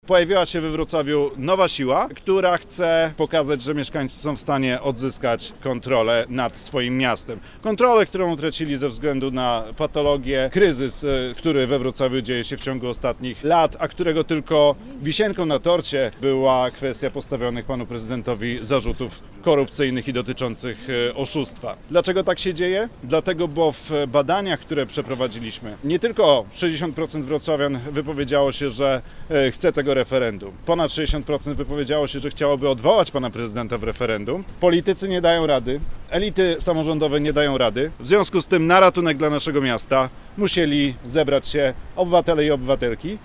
Badania, które przeprowadziliśmy wśród mieszkańców, pokazują że 60% wrocławian chce referendum – mówi Piotr Uhle, radny miejski i prezes stowarzyszenia „SOS Wrocław”.